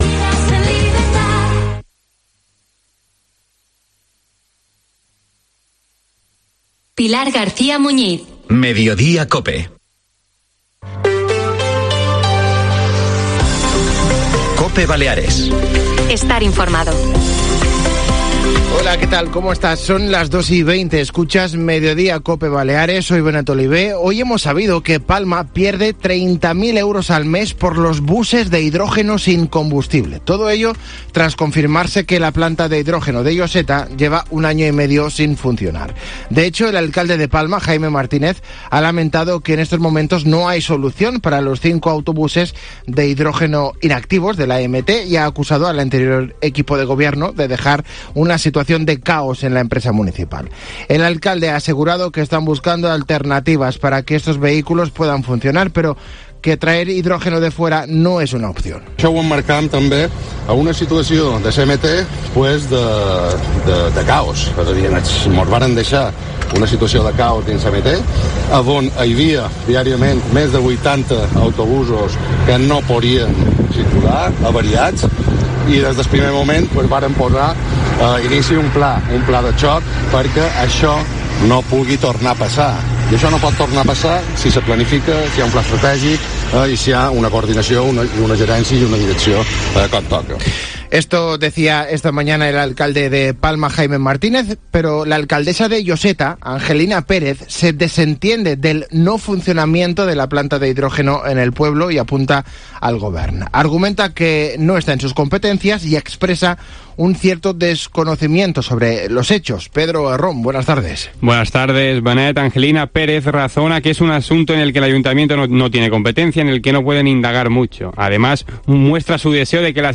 Informativo del mediodía